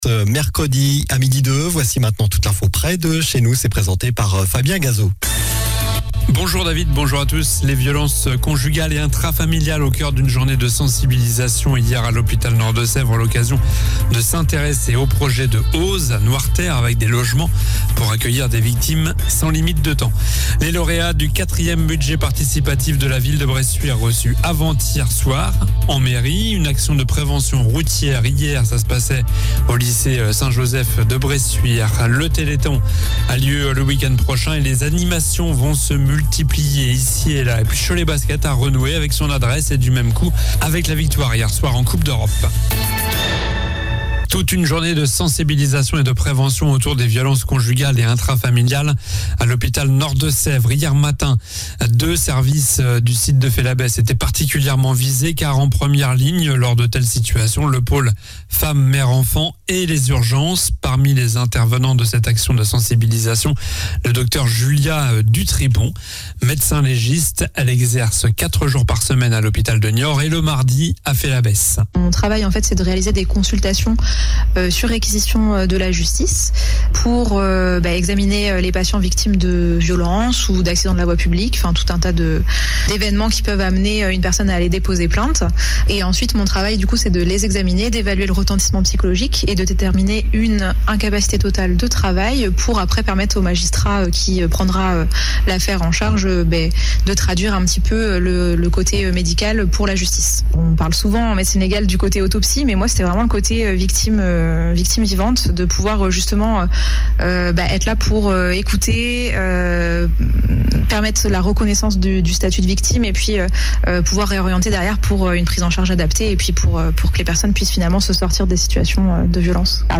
Journal du mercredi 06 décembre (midi)